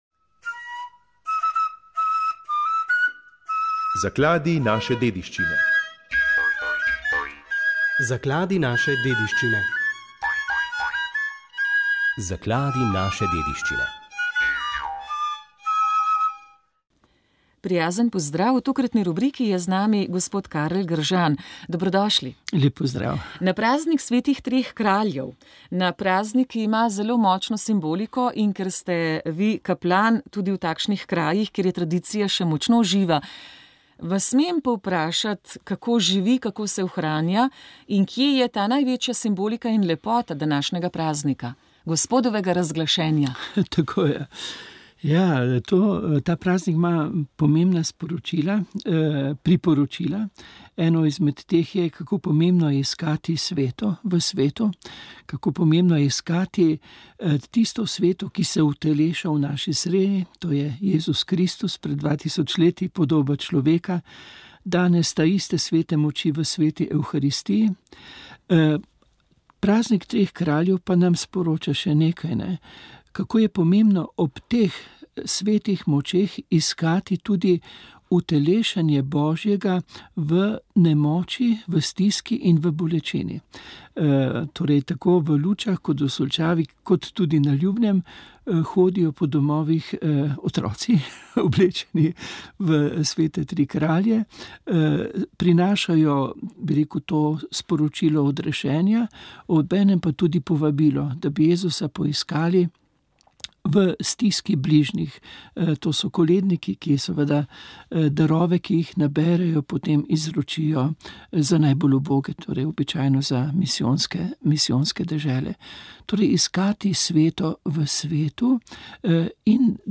Rožni venec
Molili so bogoslovci.